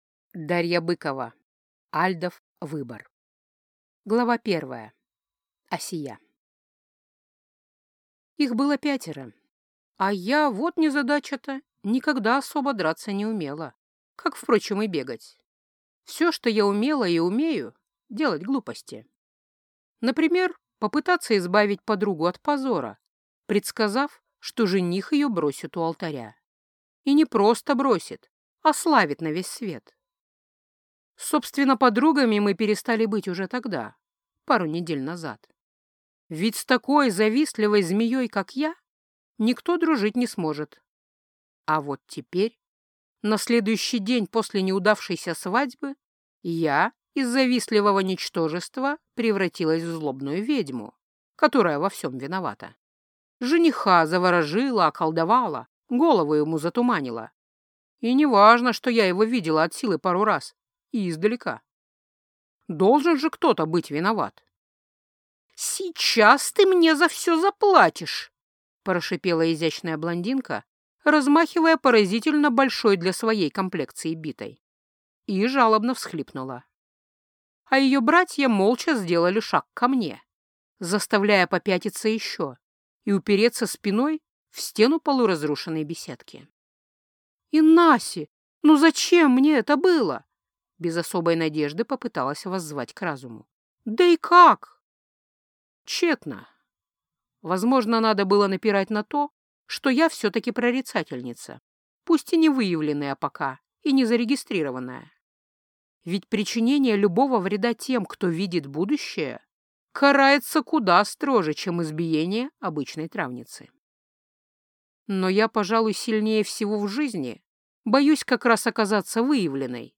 Аудиокнига Альдов выбор | Библиотека аудиокниг